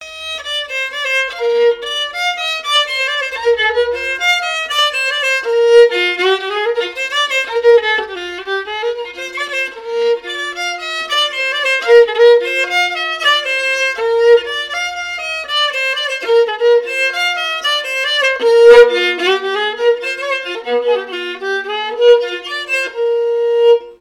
Couplets à danser
branle : avant-deux
répertoire de chansons, et d'airs à danser
Pièce musicale inédite